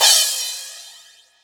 • Short Reverb Cymbal Sound Clip F Key 02.wav
Royality free cymbal sound clip tuned to the F note. Loudest frequency: 5662Hz
short-reverb-cymbal-sound-clip-f-key-02-hWY.wav